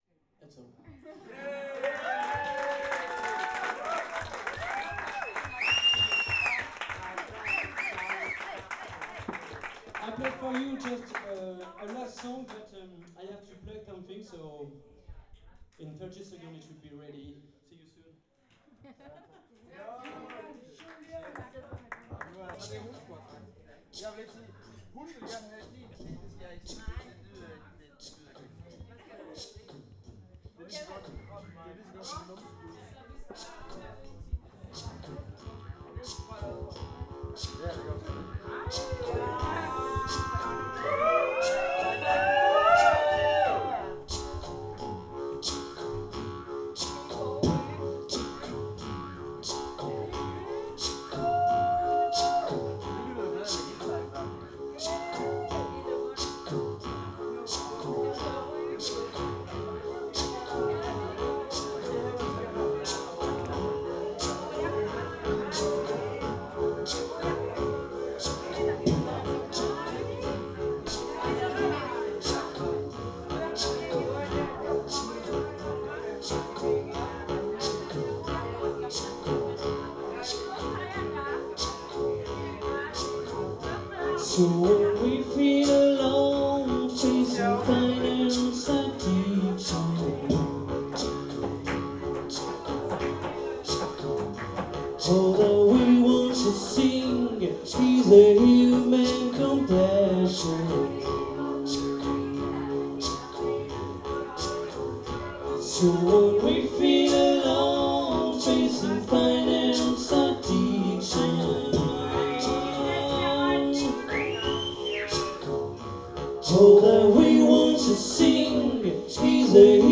Une soirée mémorable, inoubliable, que nous et tous ont apprécié !
Un petit extrait de cette superbe soirée: